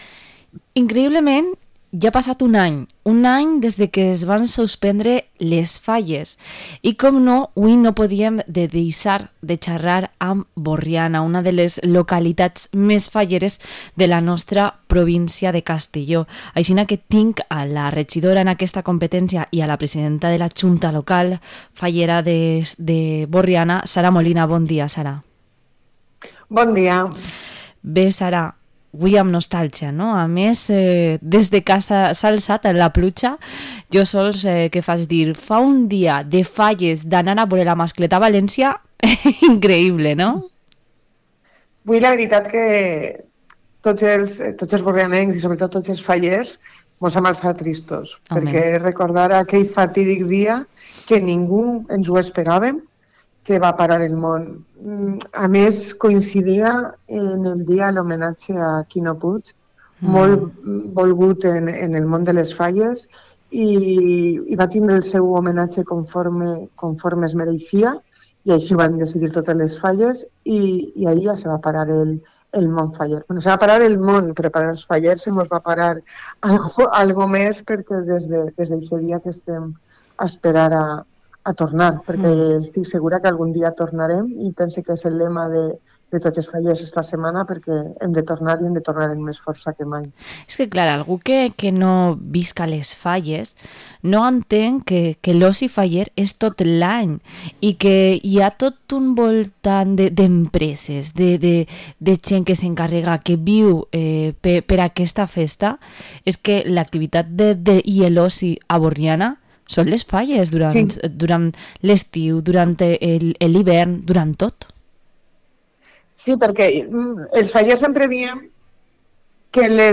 Entrevista a la concejala de Fallas y presidenta de la Junta Local Fallera de Burriana, Sara Molina